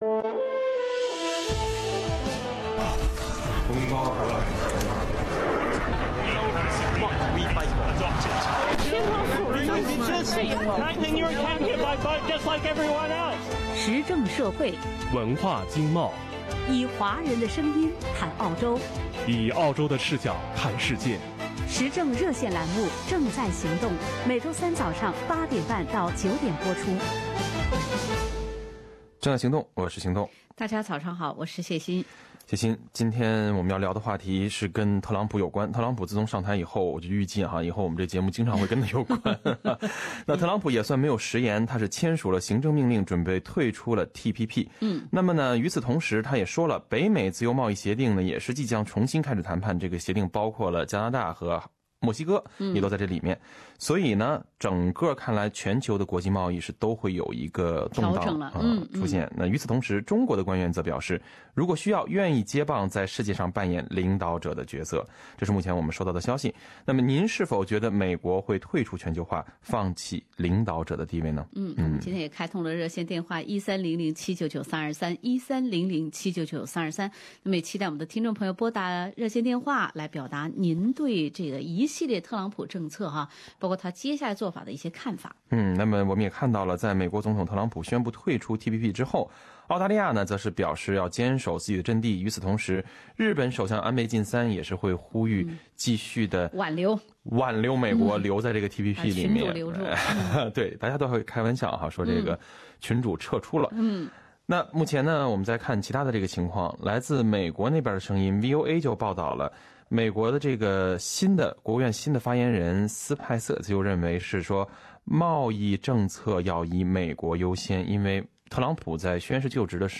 您觉得美国会退出全球化，放弃领导者的地位吗？本周三八点三十分之后的《正在行动》，听众拨打热线电话参与节目讨论。